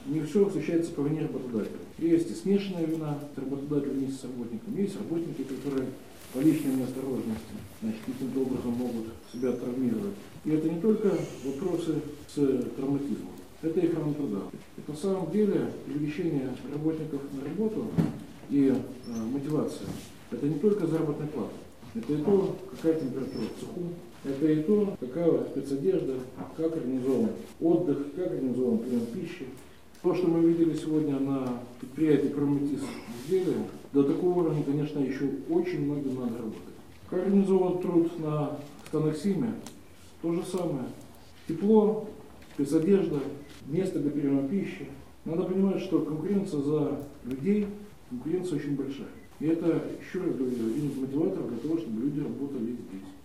Выездное заседание городского исполнительного комитета под председательством Михаила Баценко прошло на базе филиала Барановичские электрические сети РУП «Брестэнерго».
baczenko-ohrana-truda-sinhron.ogg